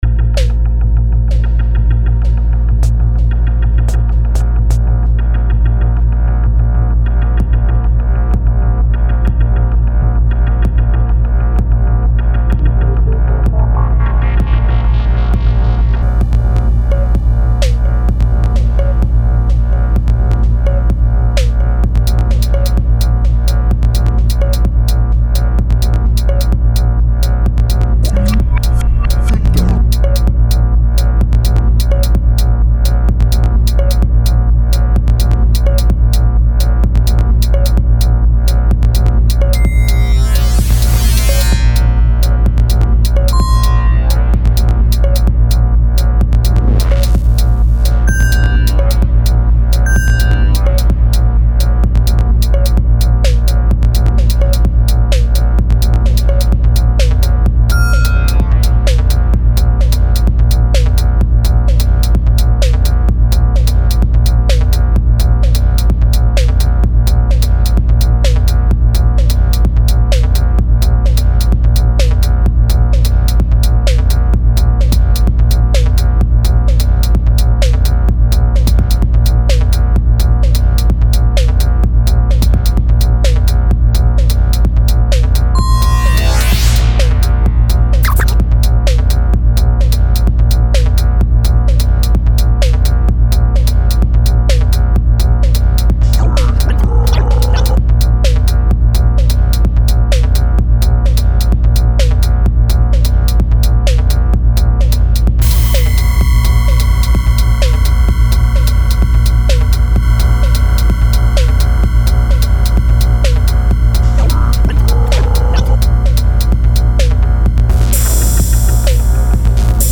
Zum Vergrößern anklicken.... das find ich ganz schön cool .... klanglich vielleicht stellenweise n bisschen schroff, aber sonst fetzt das